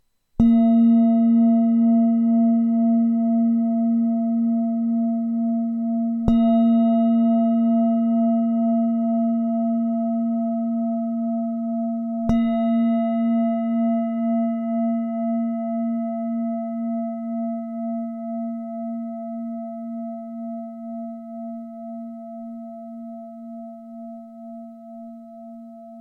Matná tibetská mísa A#3 18cm
Nahrávka mísy úderovou paličkou:
Jde o ručně tepanou tibetskou zpívající mísu dovezenou z Nepálu.